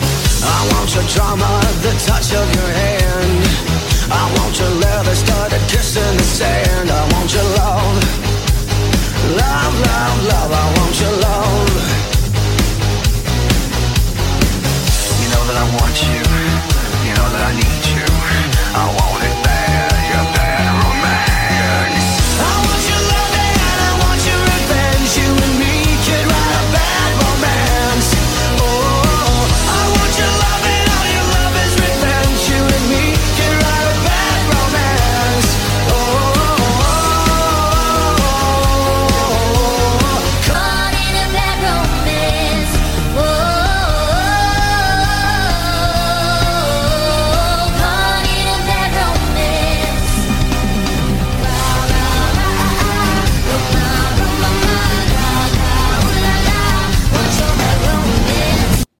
giganotosaurus & spinosaurus sings